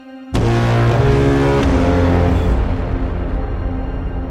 all quiet on the western front Meme Sound Effect
Category: Movie Soundboard